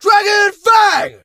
fang_ulti_vo_02.ogg